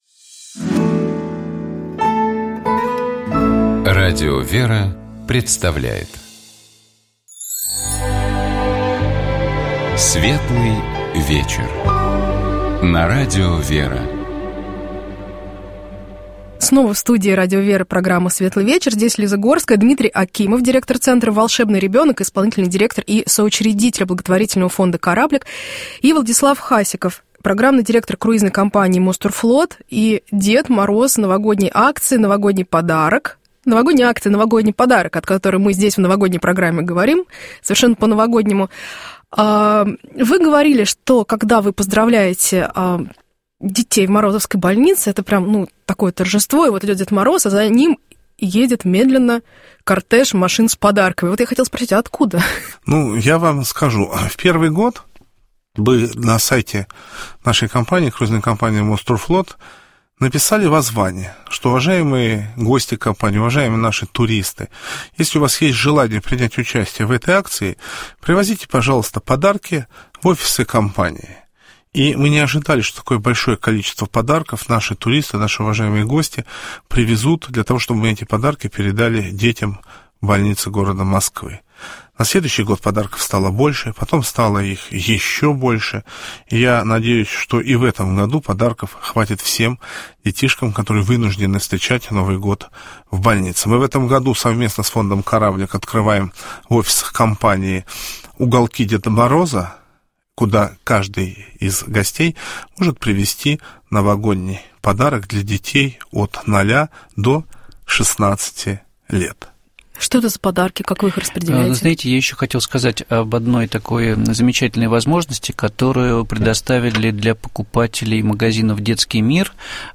Дорогие радиослушатели, в эфире радио «Вера», программа «Светлый вечер».